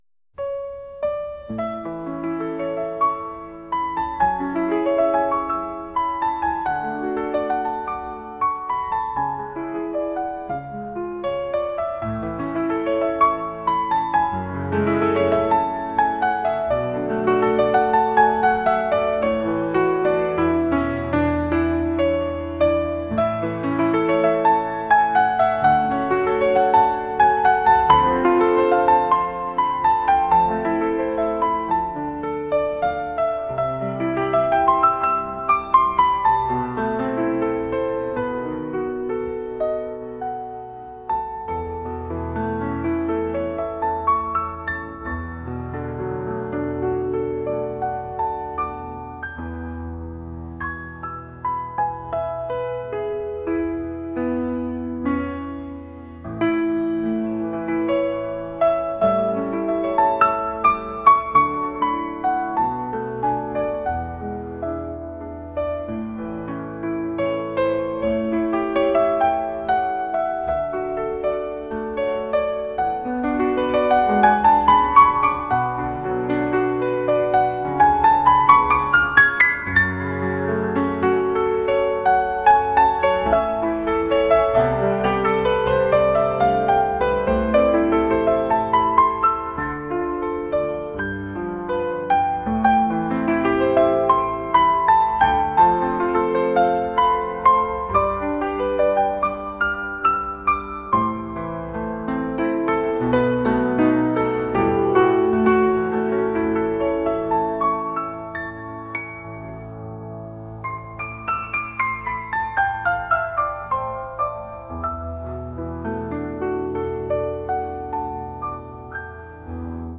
jazz | acoustic | lounge